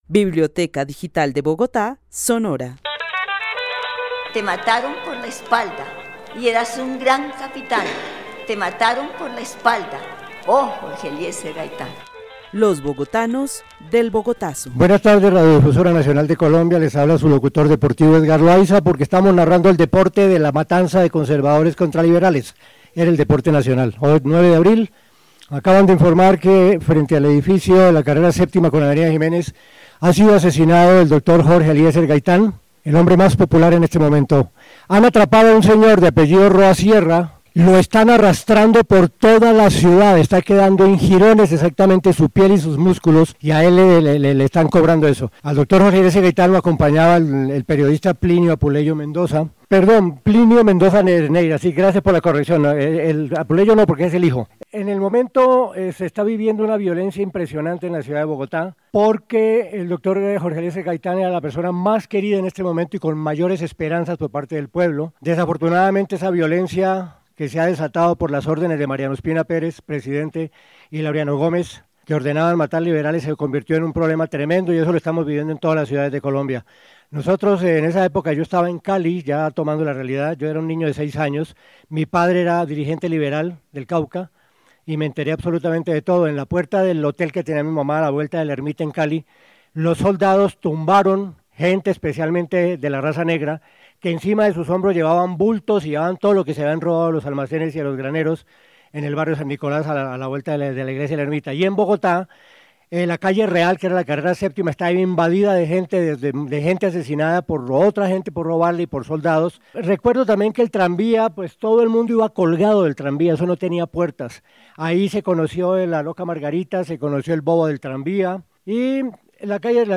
Narración oral recreando la emisión de la Radiodifusora Nacional de la noticia del asesinato de Jorge Eliecer Gaitán el 9 de abril de 1948.
El testimonio fue grabado en el marco de la actividad "Los bogotanos del Bogotazo" con el club de adultos mayores de la Biblioteca El Tunal.